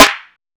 SNARE.6.NEPT.wav